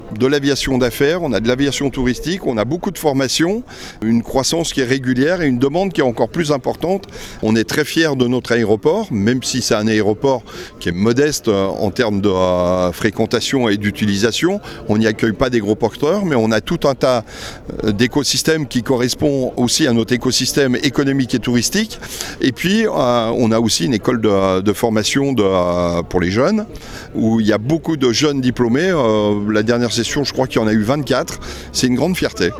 François Excoffier, Conseiller départemental délégué à l’économie et aux grands projets :